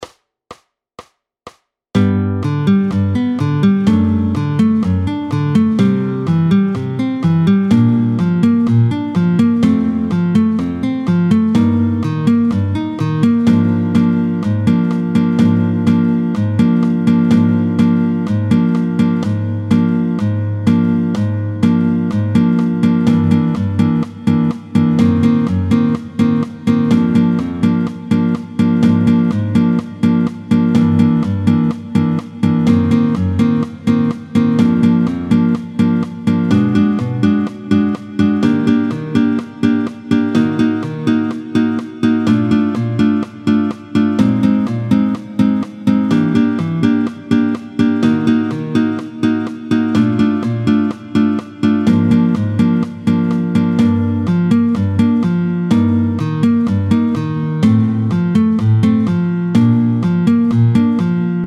guitare